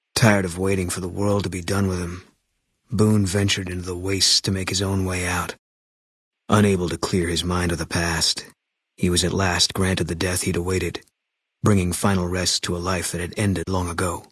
Category:Fallout: New Vegas endgame narrations Du kannst diese Datei nicht überschreiben. Dateiverwendung Die folgende Seite verwendet diese Datei: Enden (Fallout: New Vegas) Metadaten Diese Datei enthält weitere Informationen, die in der Regel von der Digitalkamera oder dem verwendeten Scanner stammen.